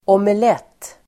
Ladda ner uttalet
Uttal: [åmel'et:]